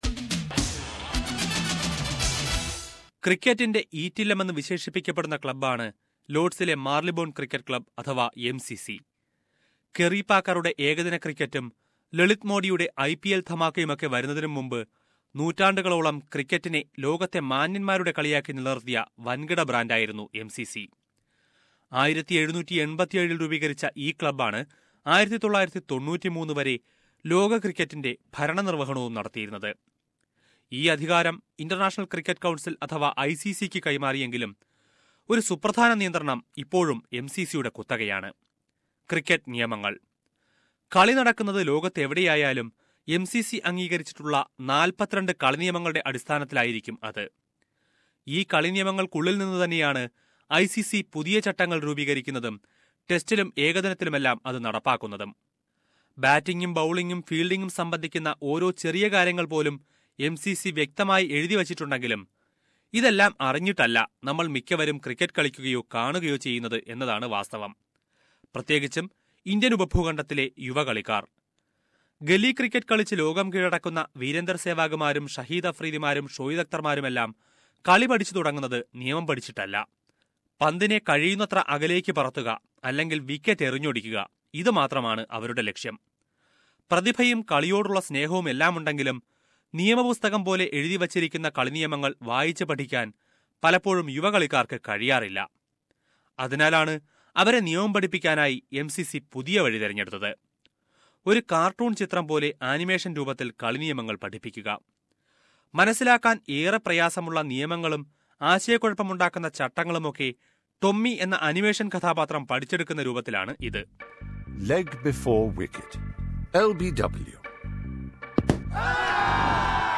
But, Marylebone Cricket Club (MCC), Lords, which is the custodian of the Laws of Cricket, released some animations to teach young cricketers and fans these laws. The release of the animations coincided with the changes in some laws that took effect from 1st of October 2013. Let us listen to a report on that...